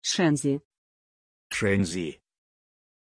Pronunciation of Shenzi
pronunciation-shenzi-ru.mp3